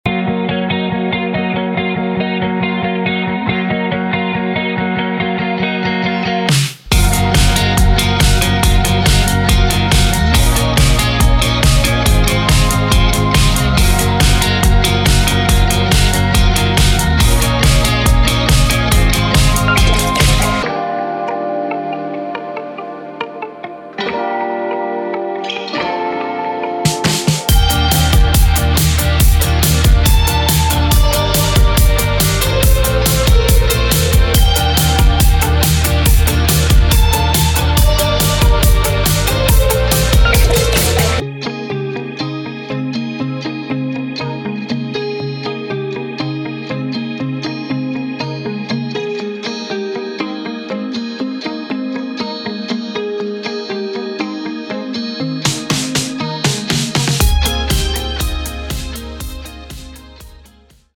Indie Rock